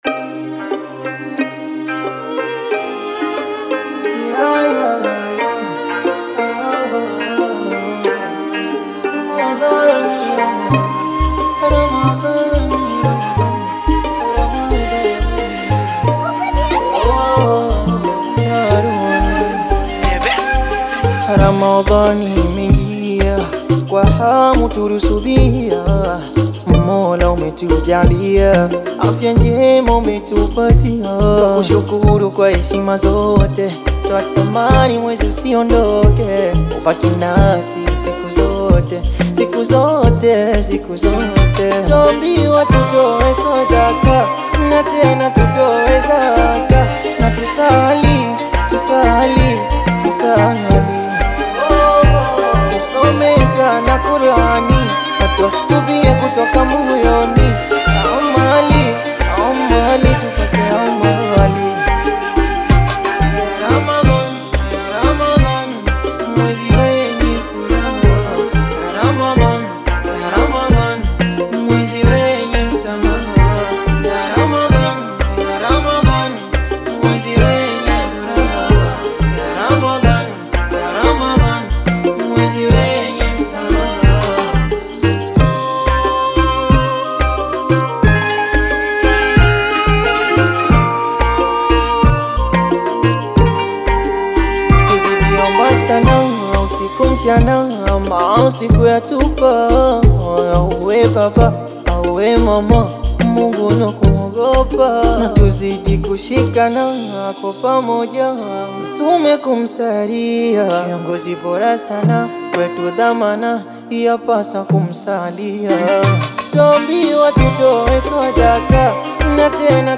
KASWIDA